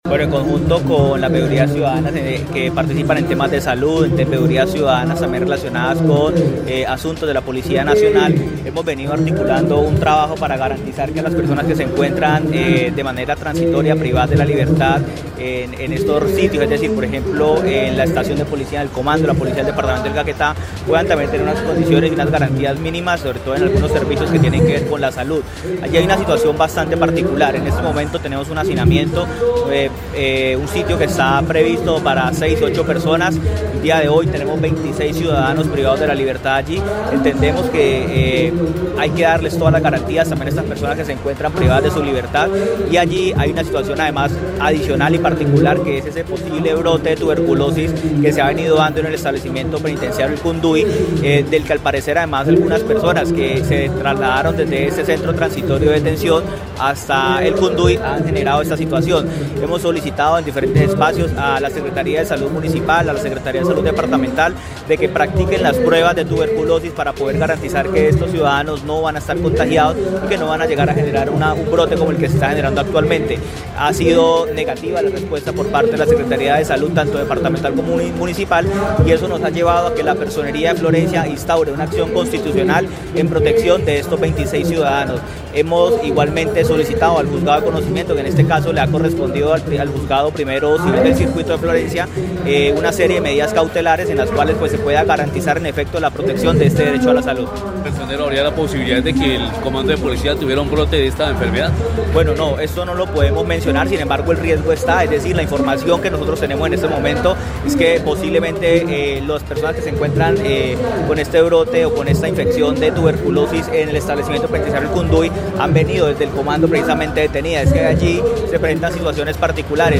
Jorge Luis Lara Andrade, personero del municipio de Florencia, denunció, además, que, junto a las veedurías ciudadanas para temas de salud, han solicitado, vía acción popular, la toma de muestras para confirmar o descartar el virus.
01_PERSONERO_JORGE_LARA_TUBERCULOSIS.mp3